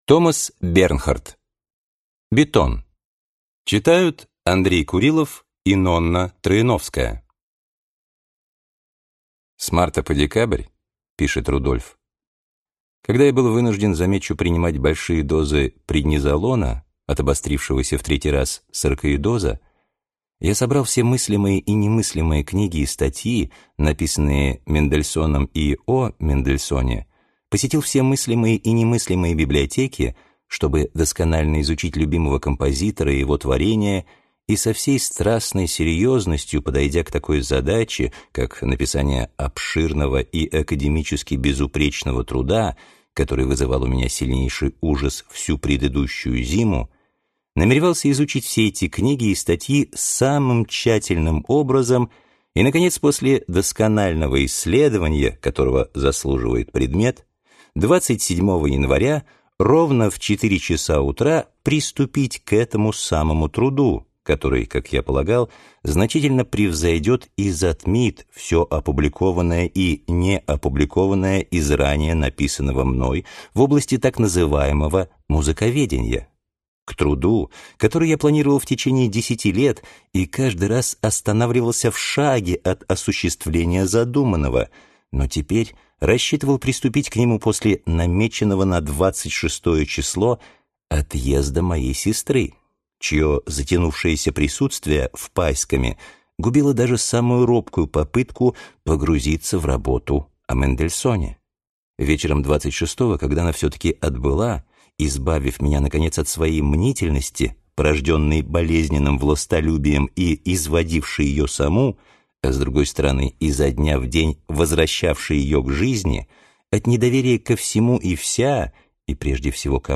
Аудиокнига Бетон | Библиотека аудиокниг